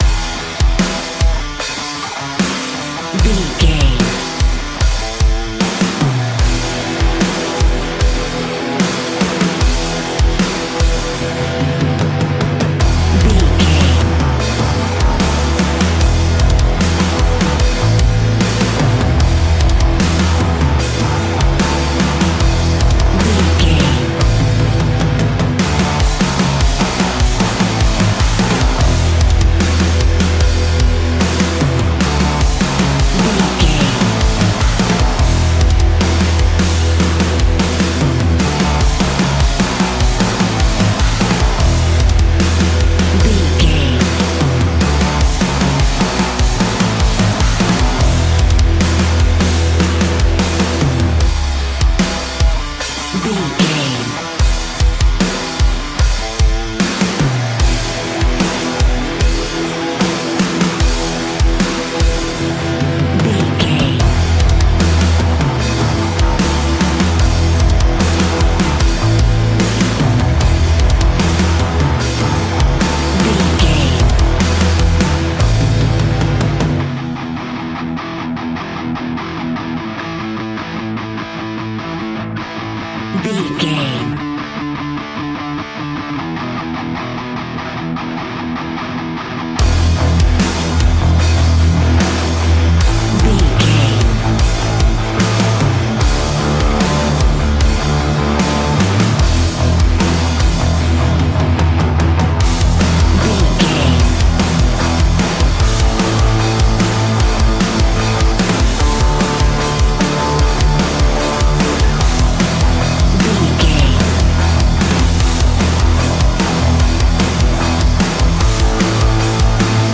Ionian/Major
E♭
hard rock
heavy metal
instrumentals